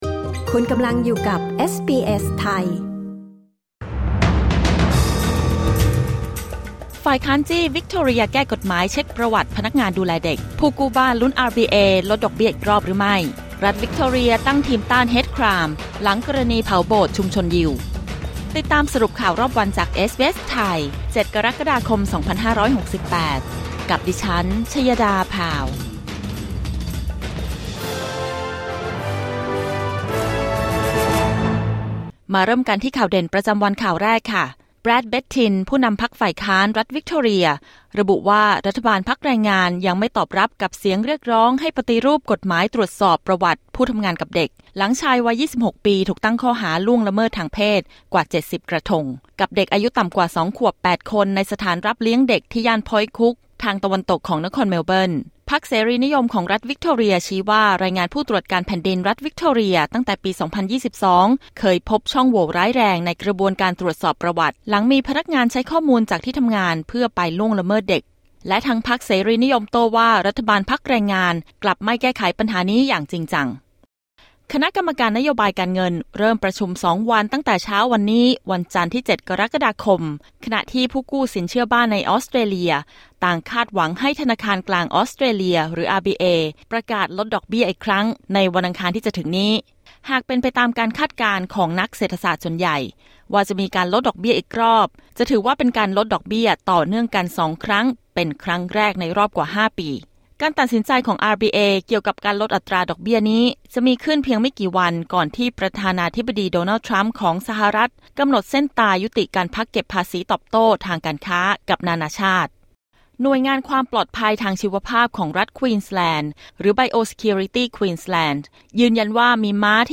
สรุปข่าวรอบวัน 07 กรกฎาคม 2568